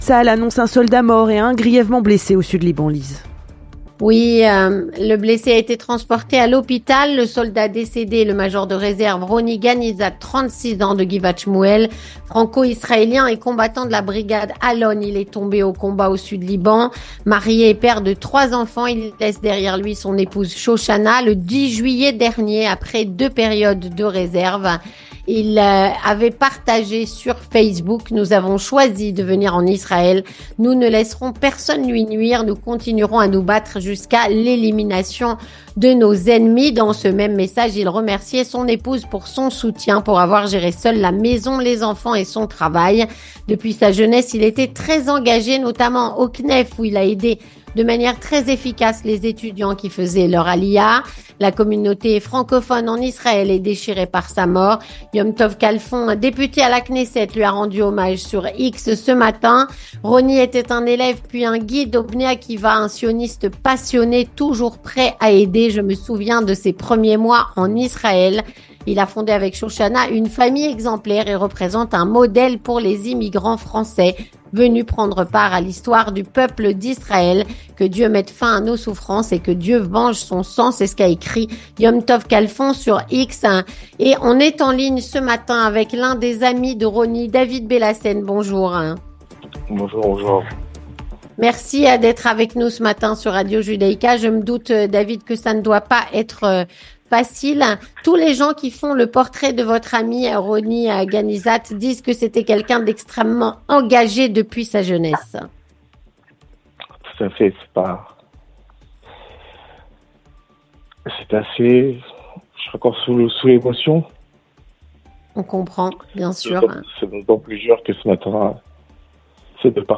3. Témoignage